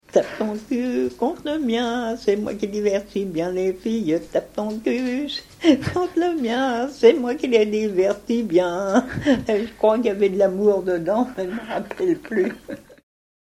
Chants brefs - A danser
Chansons traditionnelles et populaires
Pièce musicale inédite